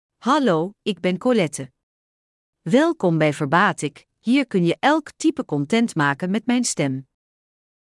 Colette — Female Dutch AI voice
Colette is a female AI voice for Dutch (Netherlands).
Voice sample
Listen to Colette's female Dutch voice.
Female
Colette delivers clear pronunciation with authentic Netherlands Dutch intonation, making your content sound professionally produced.